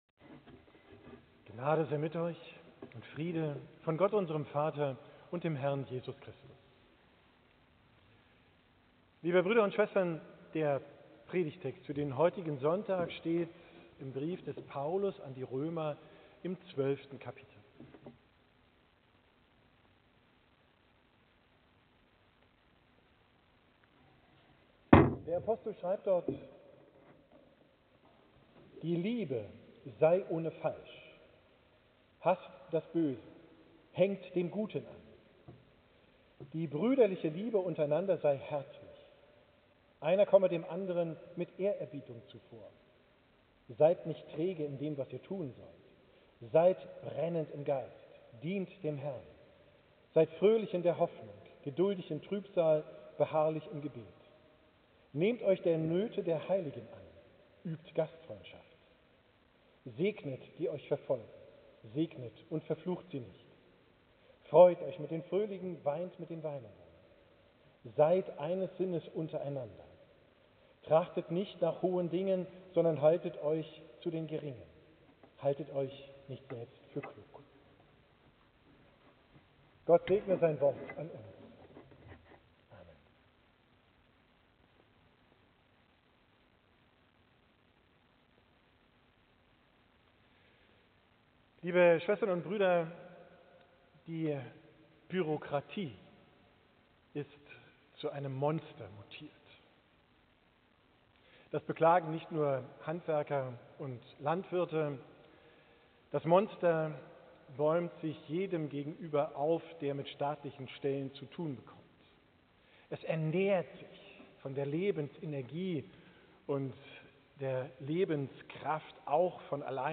Predigt vom 2. Sonntag nach Epiphanias, 19. Jan. 2025